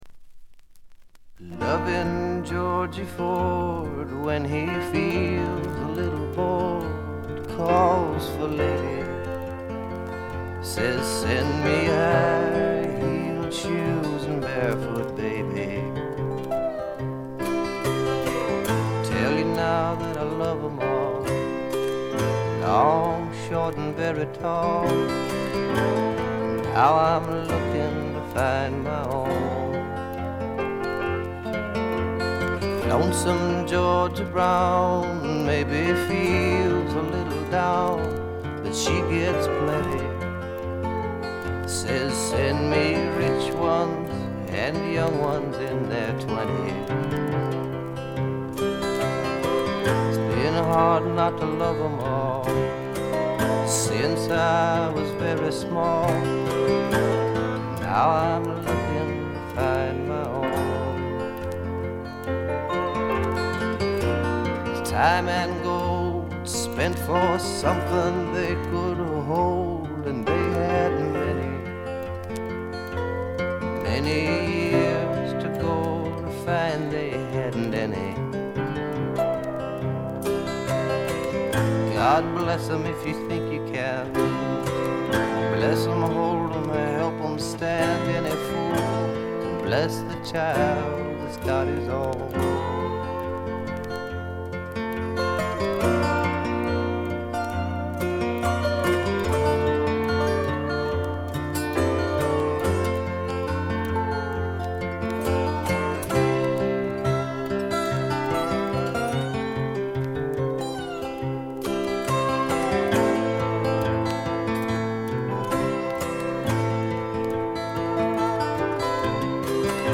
いわずと知れたベアズヴィル録音の超絶名盤です。
試聴曲は現品からの取り込み音源です。